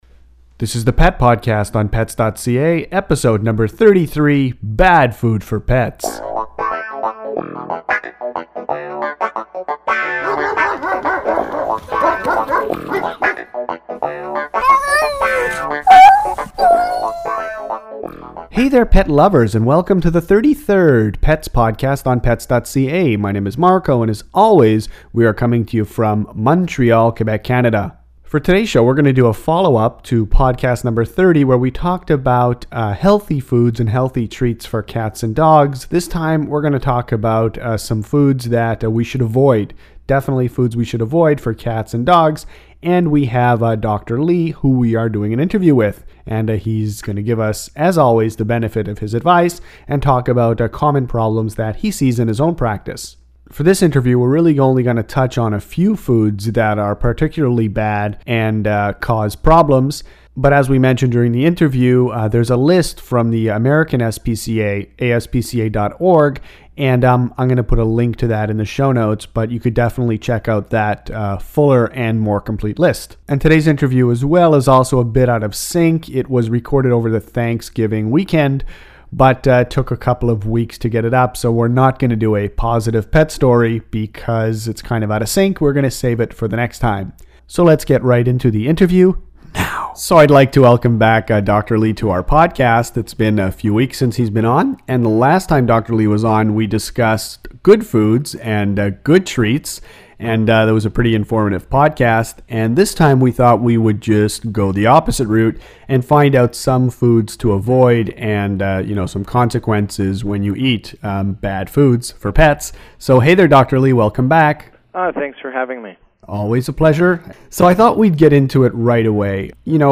Pet podcast #33 features an interview